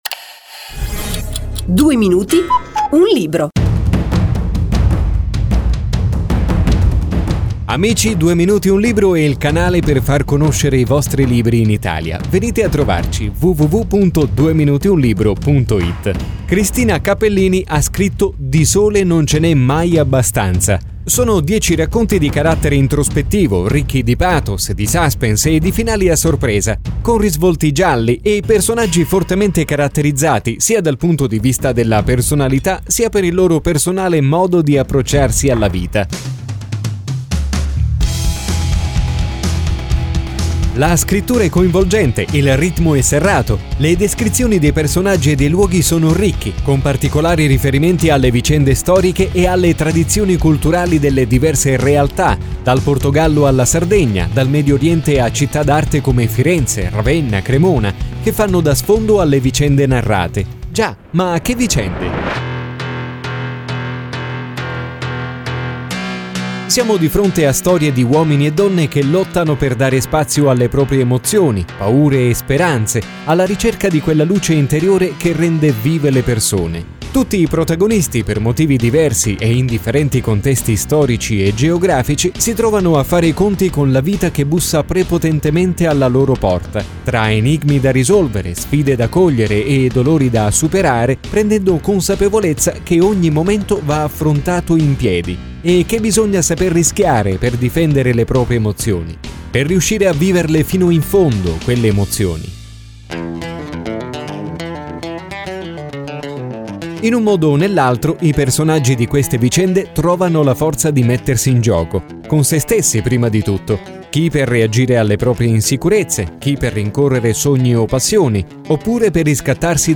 in 120 radio con il programma DUEMINUTIUNLIBRO